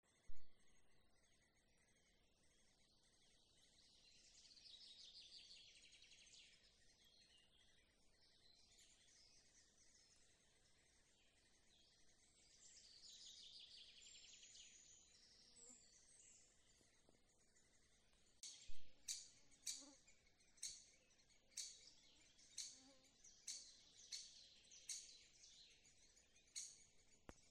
Great Spotted Woodpecker, Dendrocopos major
StatusInhabited nest or cavity
NotesAlkšņa kokā, piesaistīja mazuļu sīkšana dobumā, tad jau atlidoja pats dzenis ar barību knābī